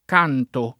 canto
vai all'elenco alfabetico delle voci ingrandisci il carattere 100% rimpicciolisci il carattere stampa invia tramite posta elettronica codividi su Facebook canto [ k # nto ] s. m. («angolo; lato») — cfr. accanto ; daccanto